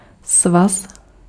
Ääntäminen
IPA : /ˈlæt.ɪs/